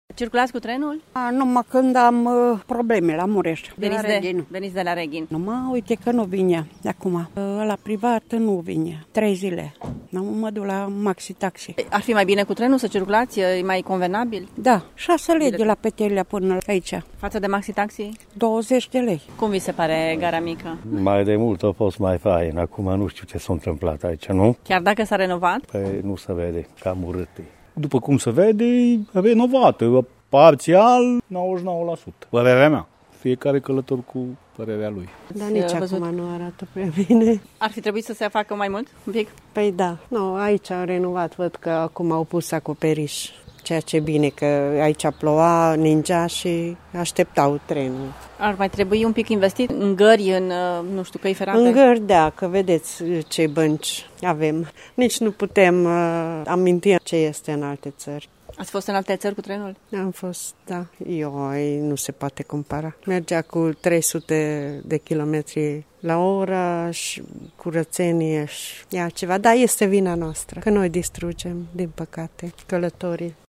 Călătorii sunt nemulțumiți că acum trebuie să plătească de patru ori prețul unui bilet de tren pentru a călători cu maxi-taxi: